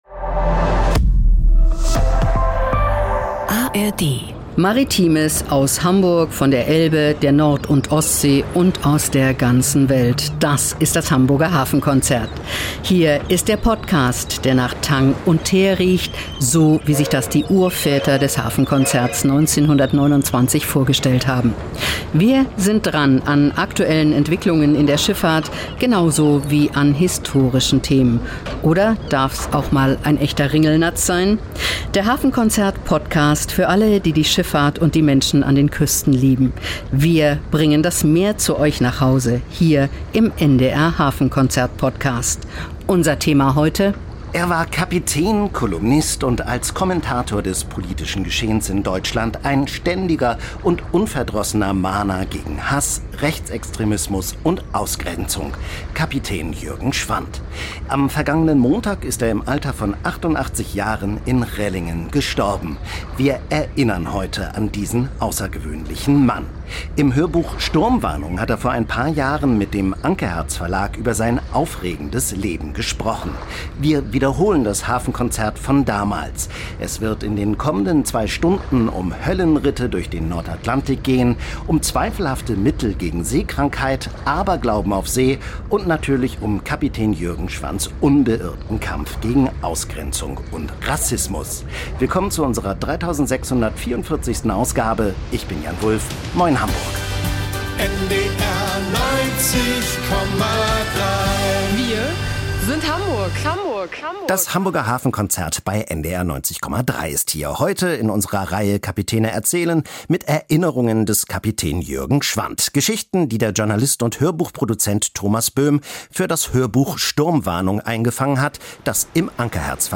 Im Hörbuch "Sturmwarnung" hat er vor ein paar Jahren mit dem Ankerherz-Verlag über sein aufregendes Leben gesprochen. Teile daraus finden sich in diesem Podcast.